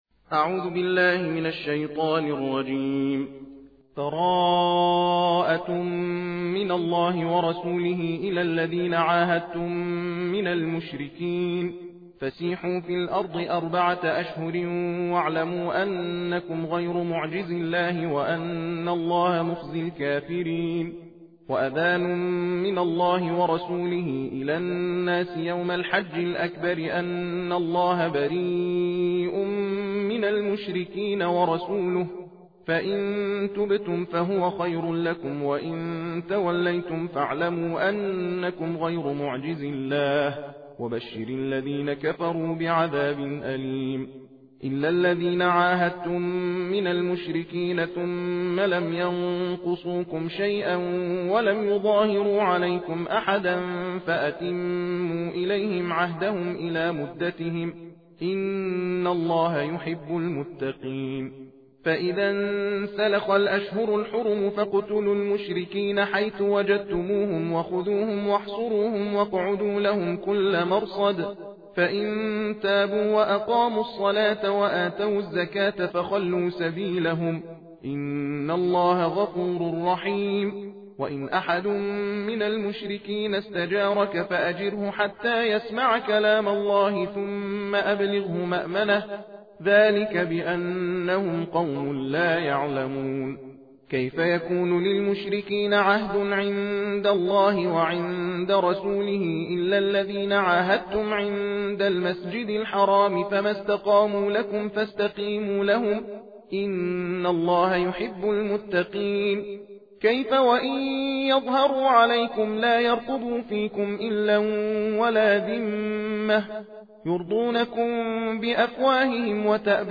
تندخوانی ( تحدیر ) سوره توبه + متن و ترجمه همراه با فضیلت سوره توبه